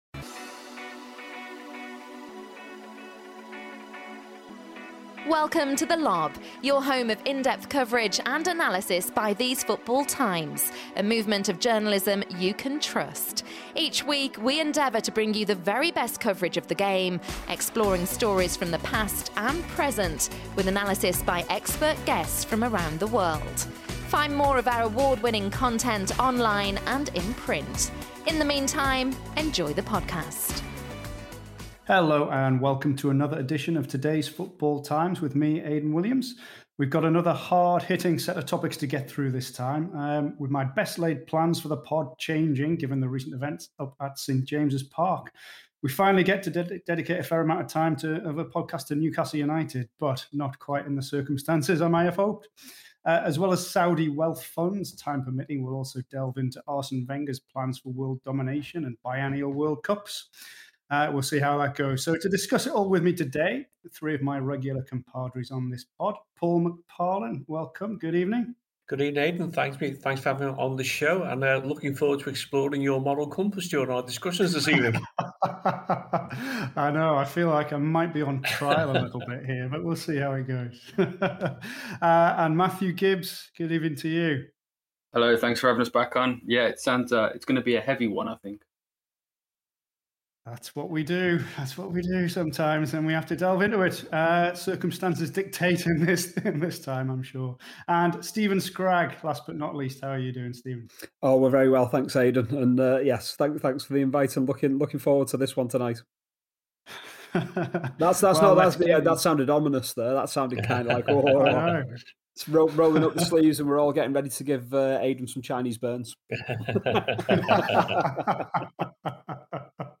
With the completion of the sale of Newcastle United, the podcast team discuss a number of issues raised by the takeover: the endless lust for money, the state of the Premier League and the impact on fans caught up in it all, and wrestling with morality and love for a club.